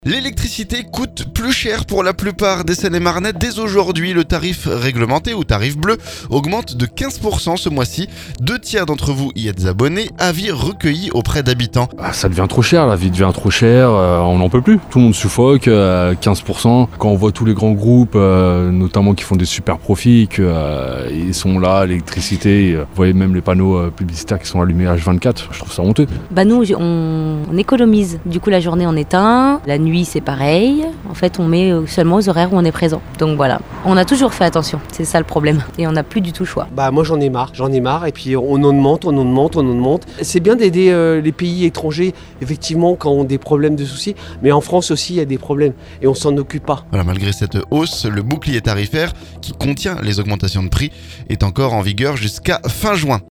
Avis recueillis auprès d'habitants.